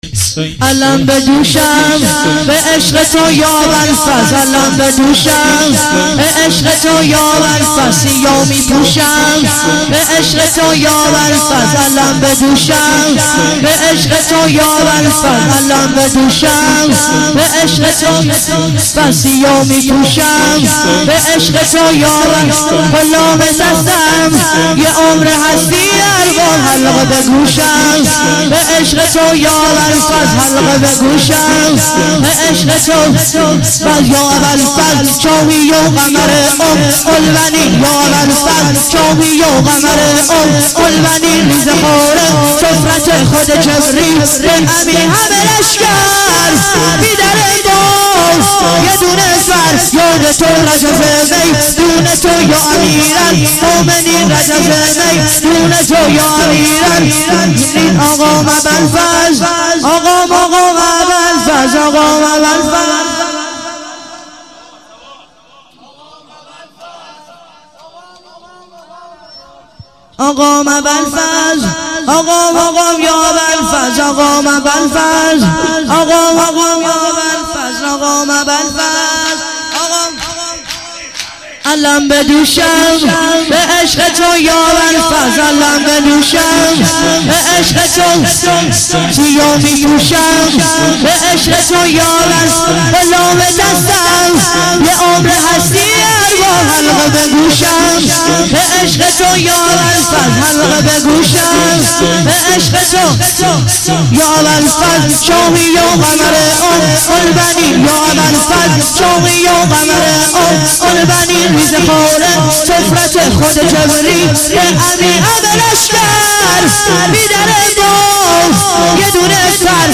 شور - علم به دوشم به عشق تو یا ابالفضل